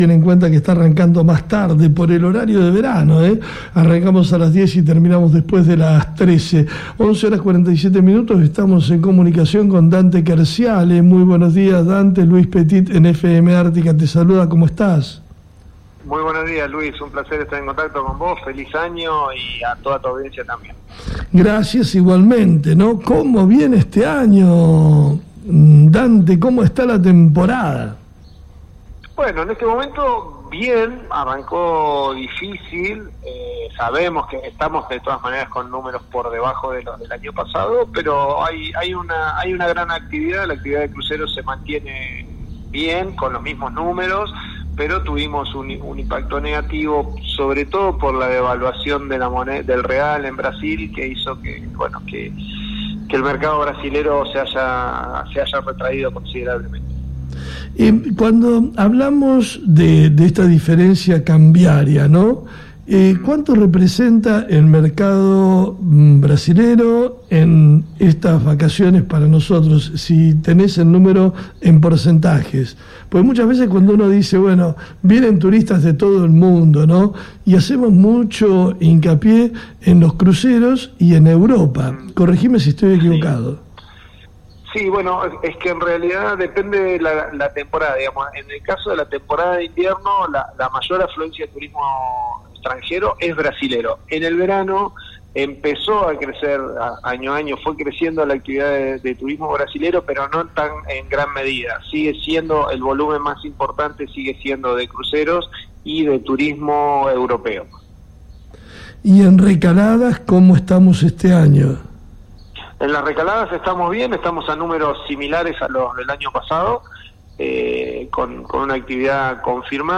Entrevistamos en FM Ártika, al Presidente de INFUETUR, Dante Querciali, para analizar como viene la temporada turística.